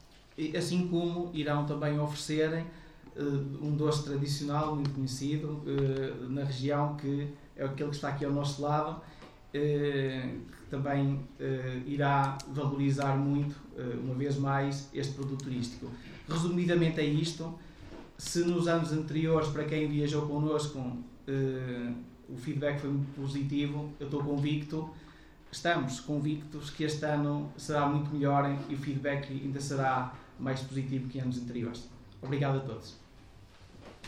Na conferência de imprensa, realizada no salão nobre da Câmara Municipal de Vila Nova de Foz Côa, no passado dia 7 de fevereiro, para a apresentação do programa das Amendoeiras em Flor, também foi promovida a Rota das Amendoeiras da CP e foi assinado um protocolo entre esta empresa de comboios e a autarquia, nesta que é a rota mais antiga.